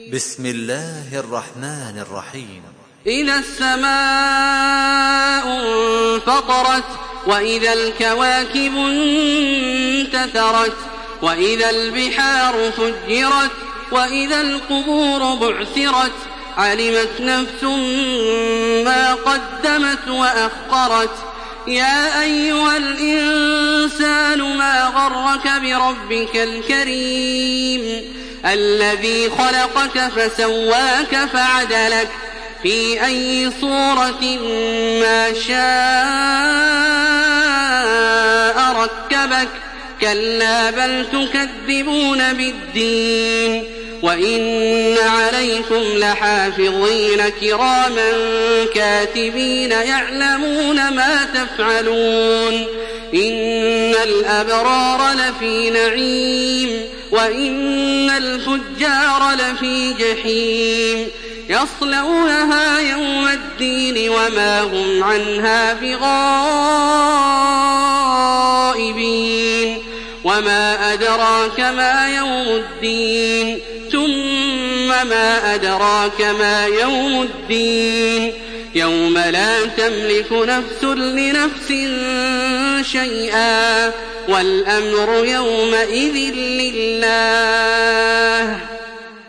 Listen and download the full recitation in MP3 format via direct and fast links in multiple qualities to your mobile phone.
تراويح الحرم المكي 1427
مرتل